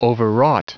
Prononciation du mot overwrought en anglais (fichier audio)
Prononciation du mot : overwrought